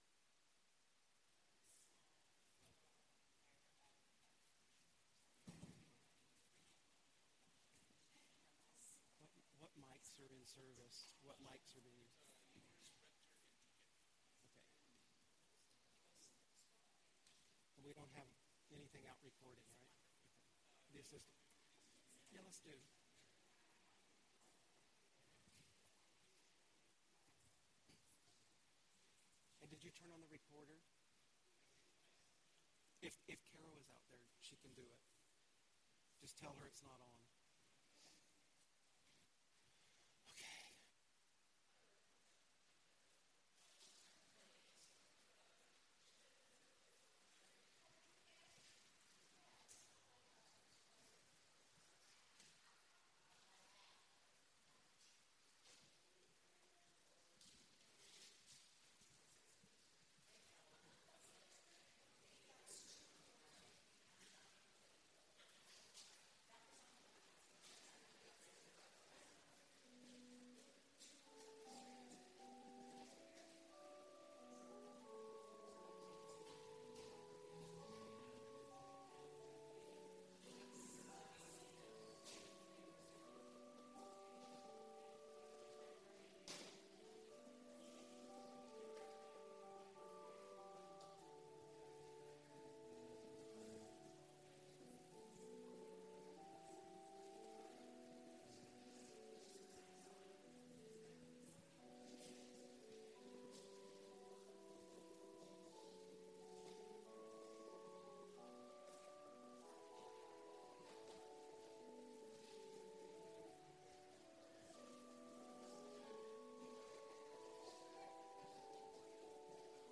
Lessons and Carols
Well, it’s not exactly a sermon but the lessons and carols speak for themselves.